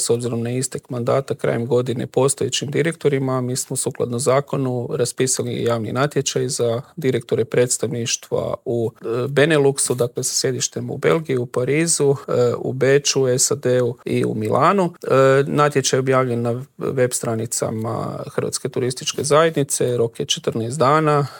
Brojke za prvih pola godine su izrazito dobre, a u Intervjuu tjedna Media servisa prokomentirao ih je direktor Hrvatske turističke zajednice Kristjan Staničić: